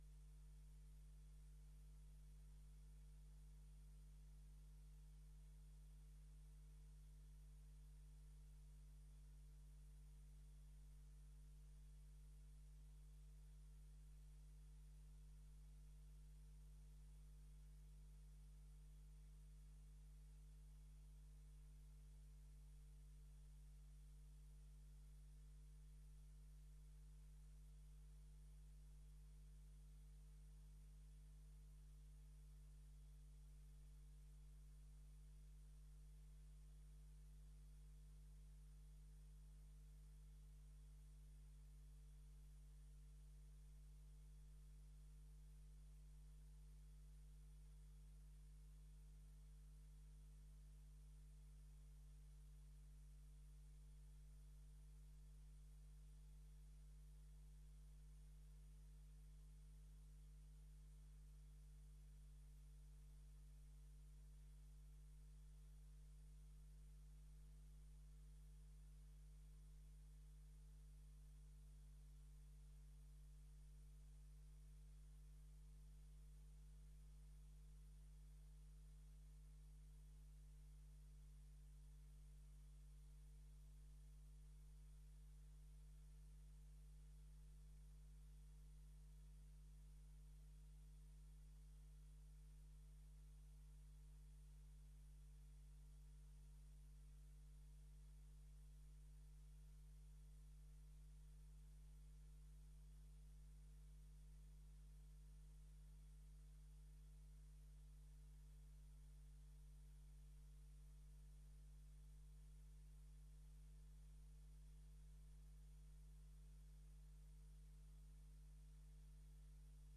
Download de volledige audio van deze vergadering
Locatie: Raadzaal Voorzitter: M.J. Holterman